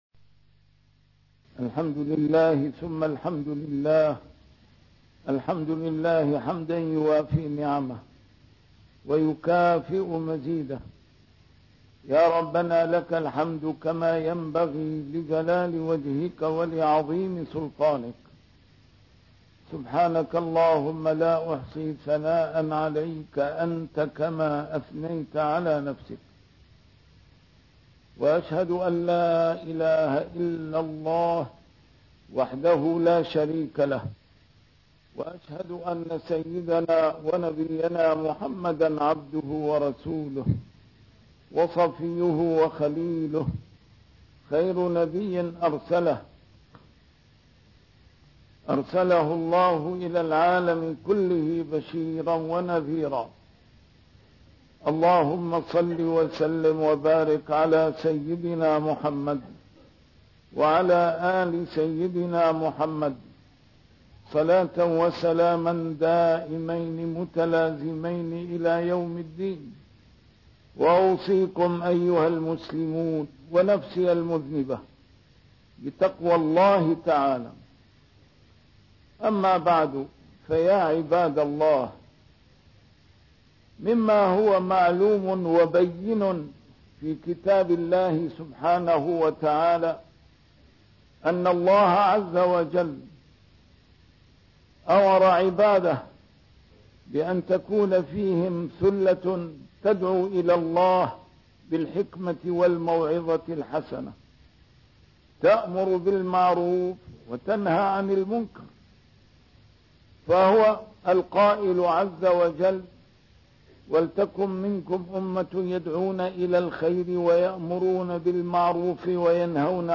نسيم الشام › A MARTYR SCHOLAR: IMAM MUHAMMAD SAEED RAMADAN AL-BOUTI - الخطب - حسن الظن بعباد الله من آداب الدعاة